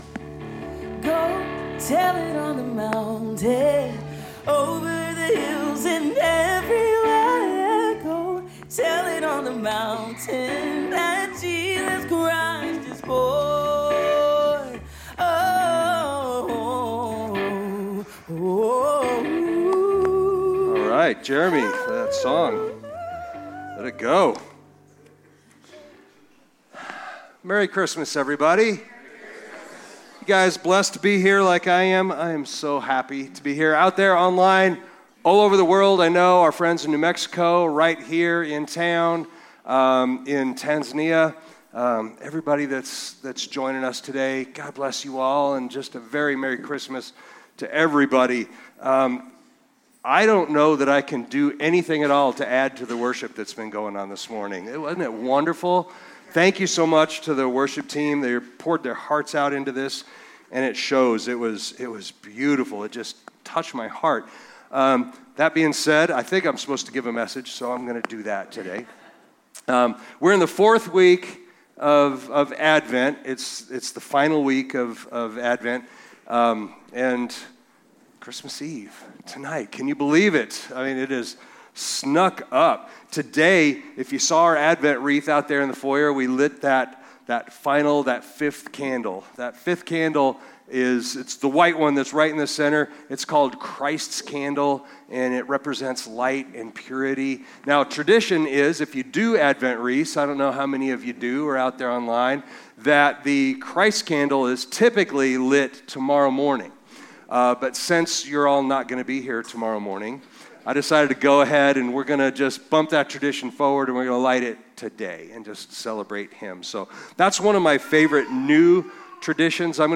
He emphasizes that Jesus’ birth fulfills ancient prophecy, reveals God’s long planned redemption, and evokes Mary’s profound joy expressed in the Magnificat. The service concludes by taking communion, remembering that the child in the manger was born for the purpose of giving His life for our salvation.